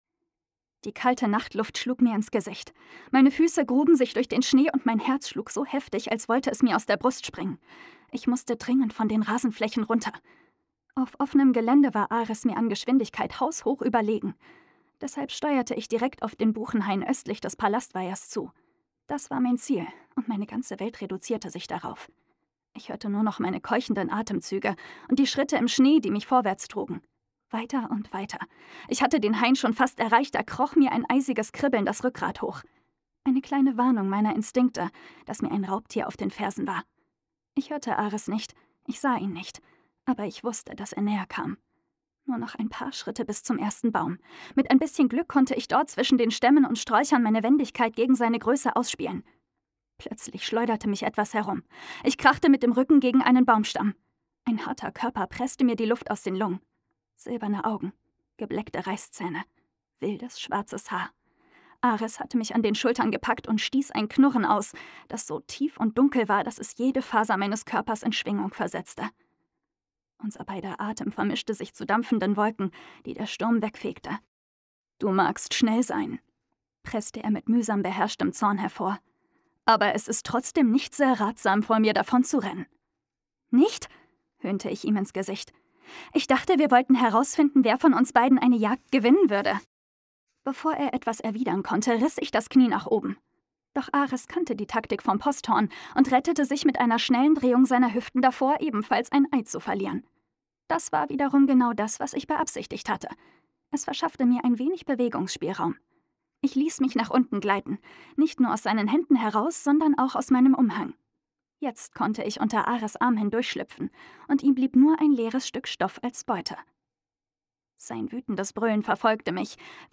Stimmfarbe: hell, jugendlich, weich